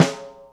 gretsch snare p.wav